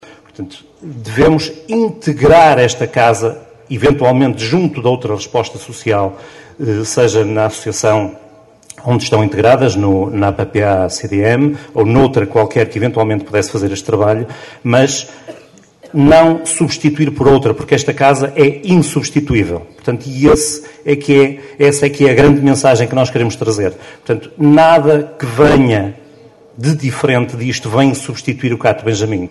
Cerca de meia centena de pessoas reuniram-se ontem ao final da tarde em Caminha para uma vigília a favor da manutenção do Centro de Acolhimento Temporário Benjamim (CAT) de Seixas, cujo encerramento por parte da APPACDM, está previsto para finais de junho.
Para o vereador da oposição o CAT Benjamim deve ser integrado junto de outra resposta social e não substituído por outro, até porque, como sublinhou, o Benjamim “é insubstituível”.